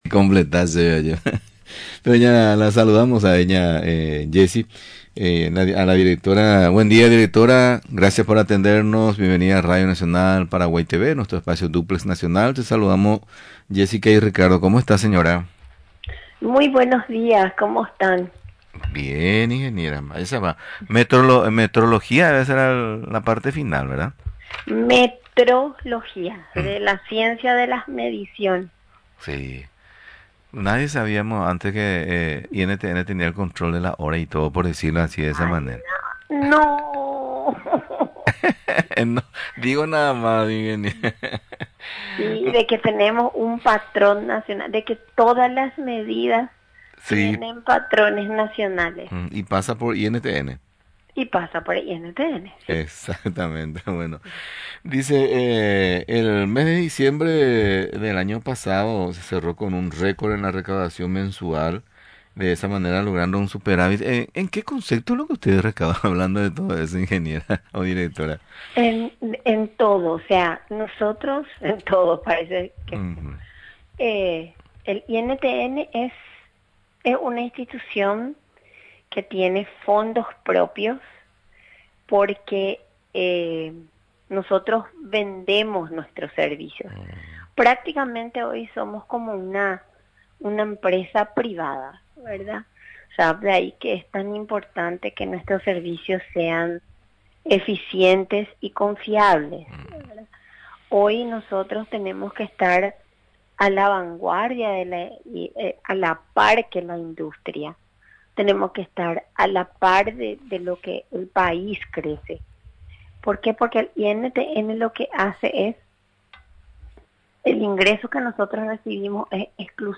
Durante la entrevista, señaló que los ingresos por venta de servicios crecieron más del 50% en los últimos cinco años, dinero que se reinvierte directamente en infraestructura y tecnología.